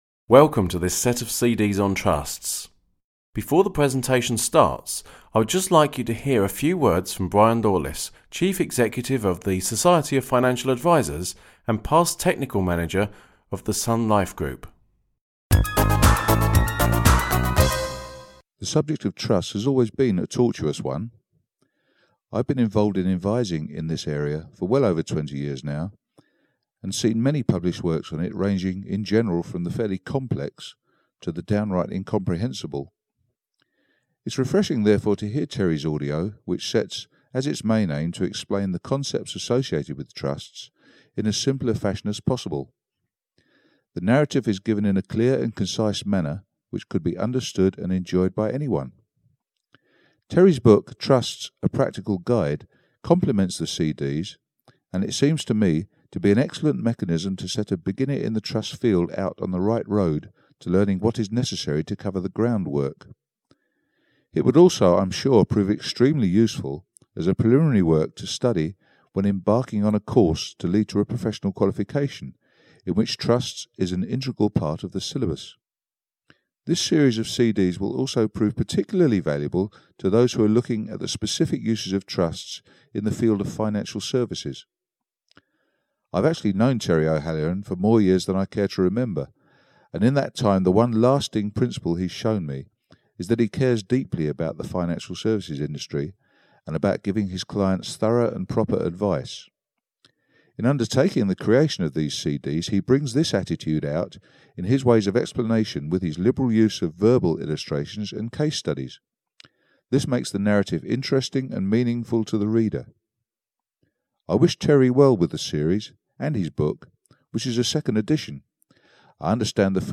Trusts - A Practical Guide 1 (EN) audiokniha
Ukázka z knihy